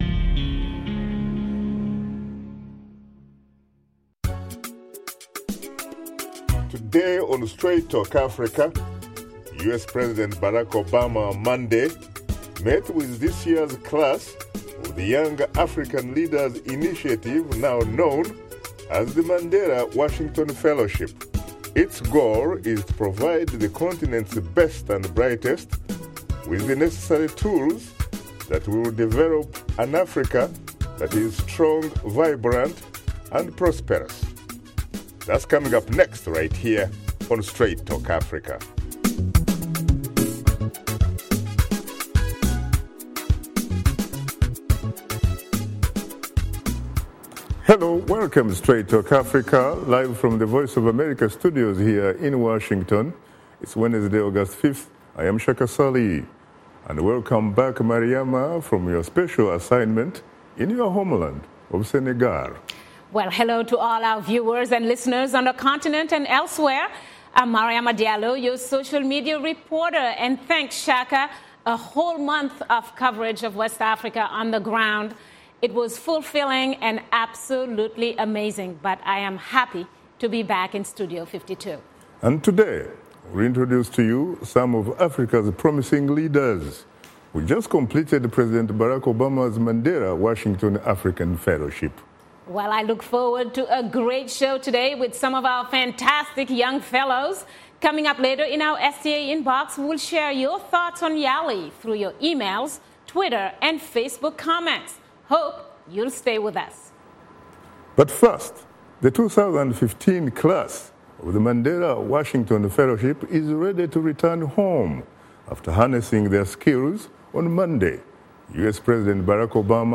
Wednesday, August 5, 2015 - Host Shaka Ssali introduces you to some of Africa’s brightest minds to share their experiences in President Obama’s Mandela Washington Fellowship for Young African Leaders program. Washington Studio Guests: